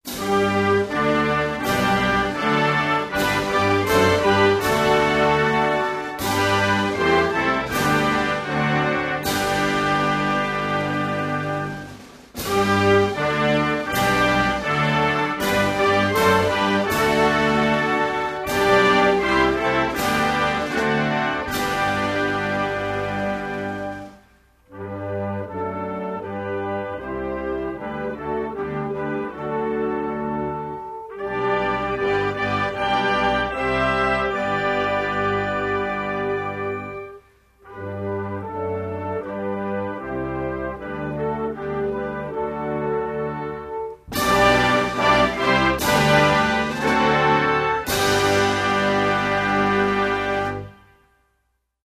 Malawian_national_anthem.mp3